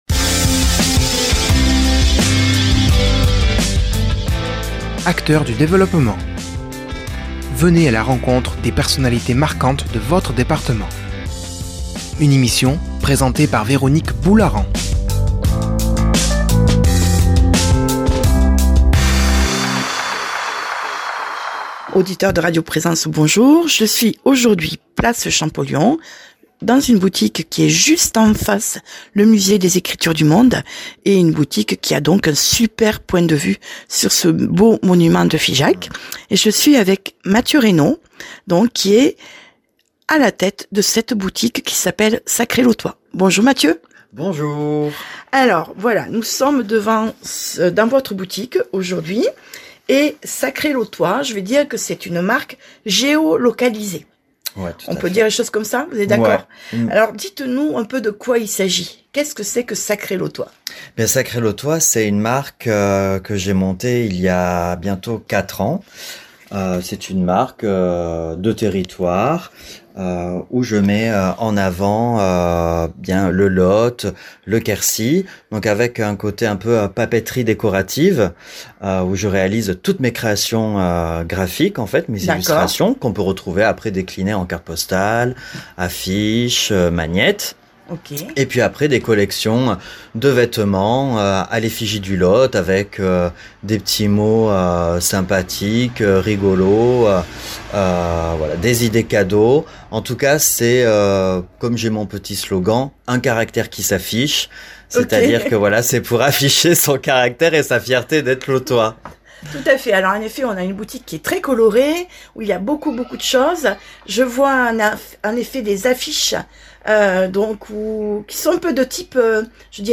a déplacé le micro de Radio Présence à quelques pas des studios.